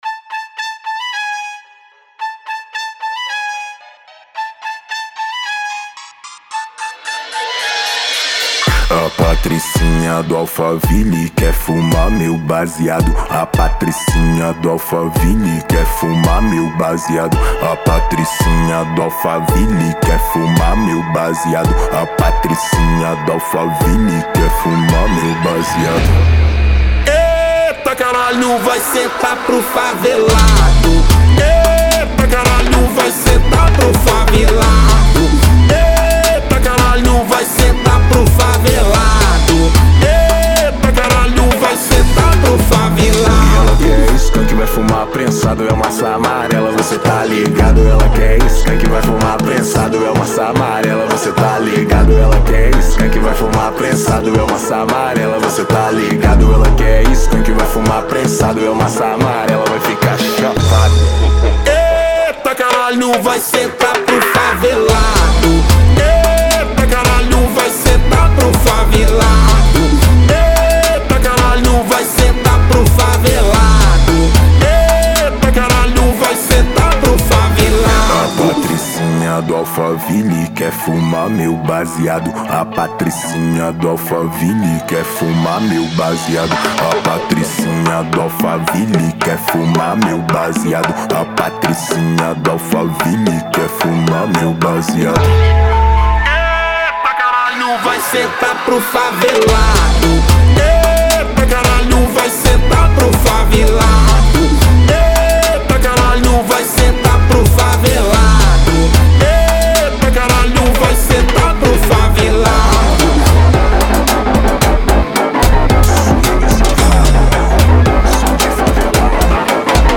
EstiloPagodão Baiano